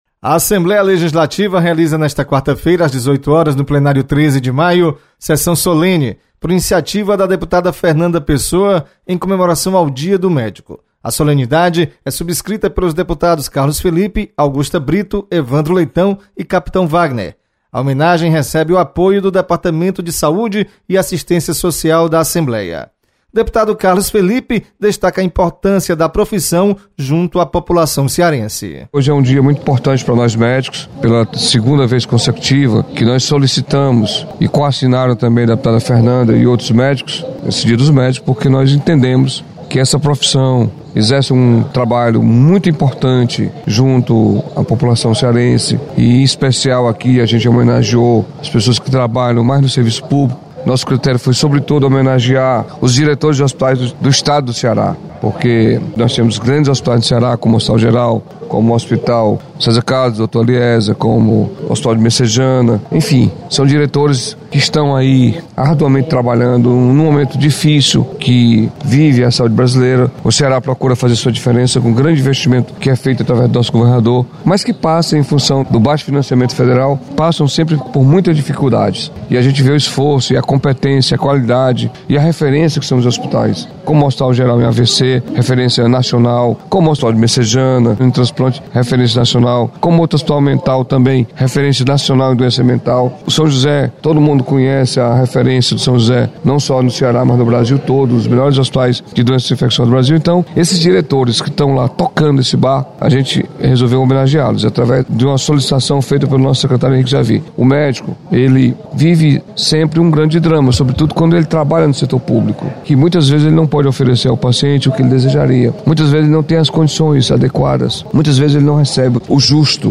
Você está aqui: Início Comunicação Rádio FM Assembleia Notícias Solenidade